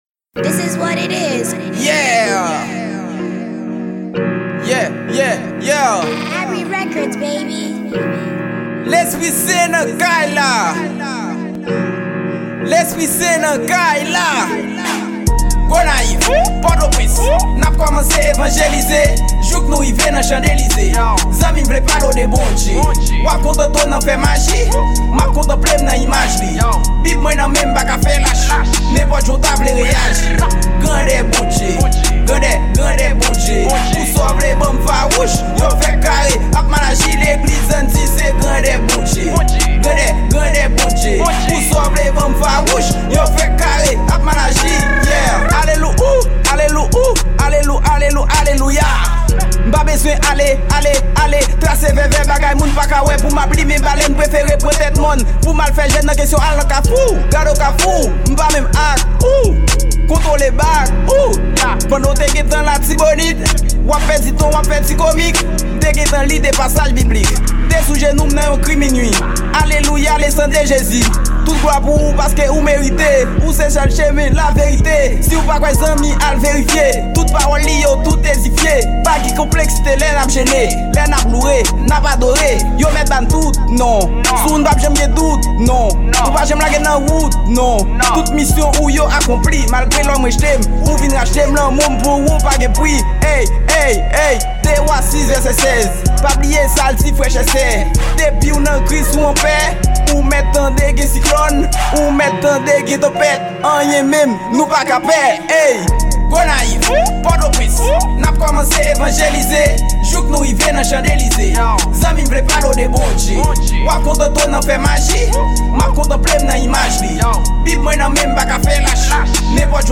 Genre : Rap